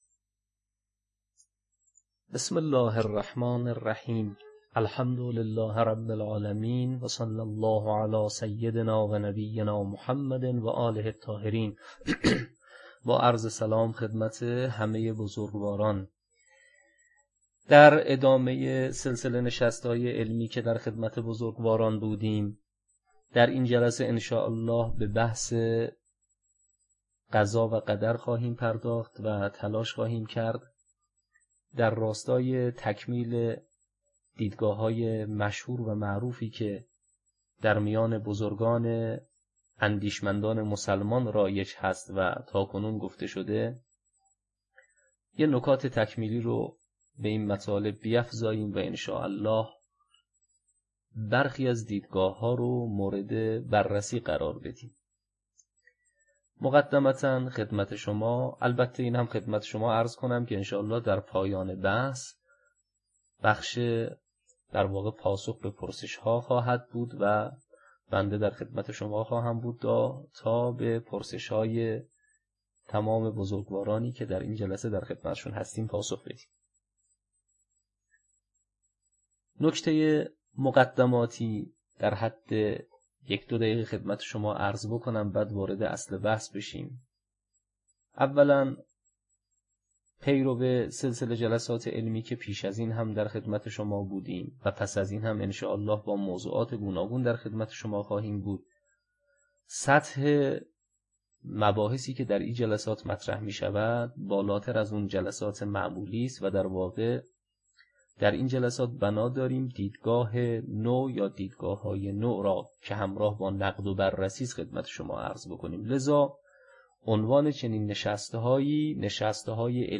فایل صوتی نشست علمی خوانشی نو از بحث قضا و قدر.mp3